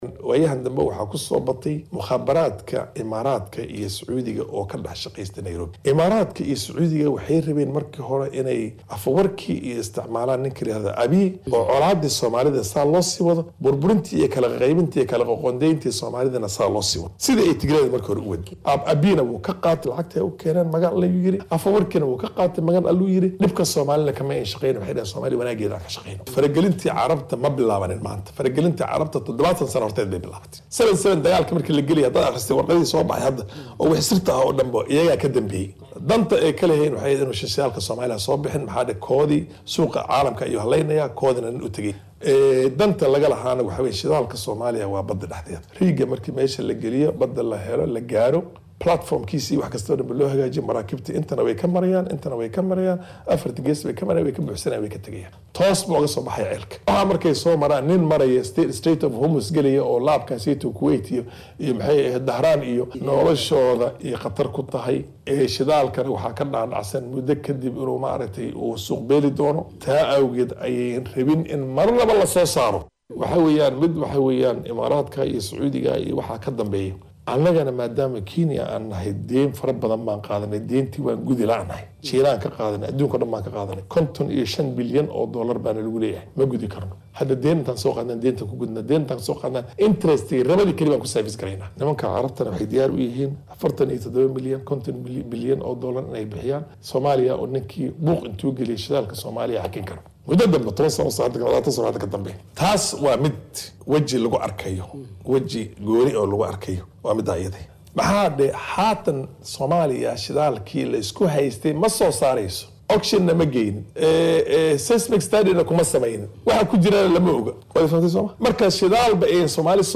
Faarax Macalin oo Wareysi siiyay Idaacadda Shabelle ayaa sheegay in dowladaha Imaaraadka Carabta iyo Sucuudi Carabiya ay yihiin dalalka hoos ka hurinaya xiisadaasi.